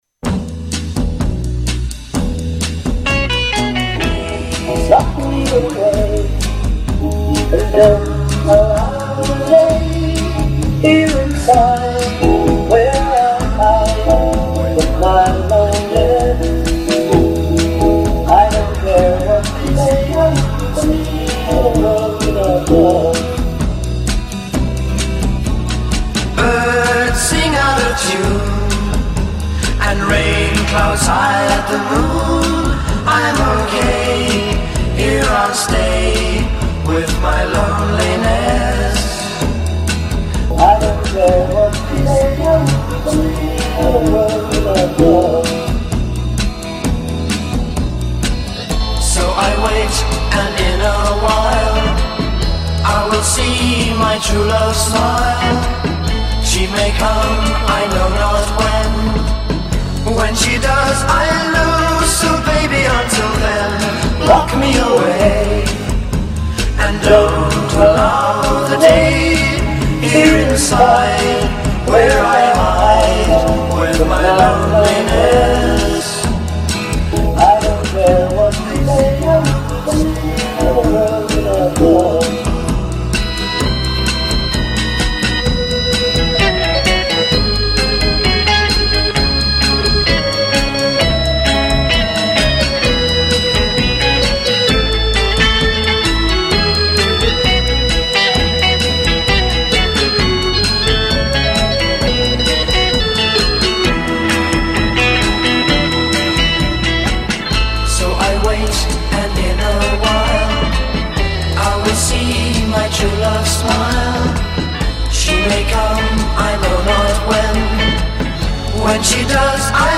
1964 demo alongside the worldwide hit.